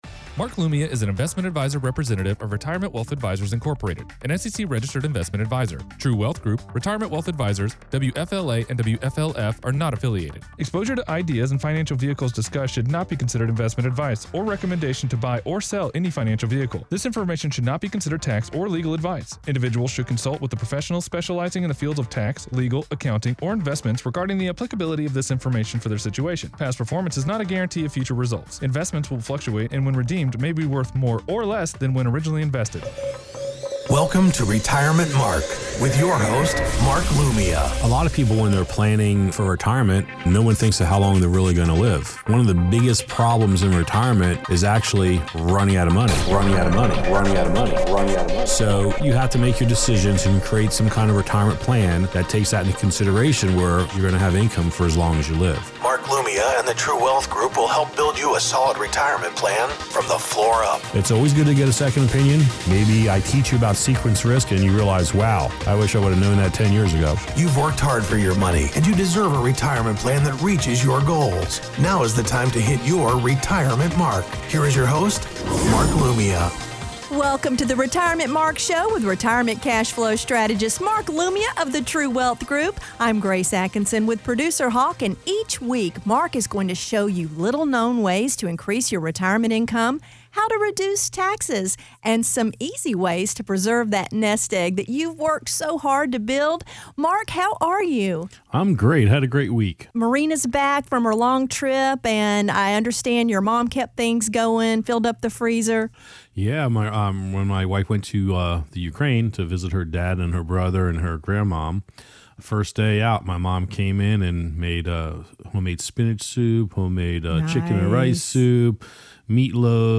Radio Show 12/3/14 Segment 1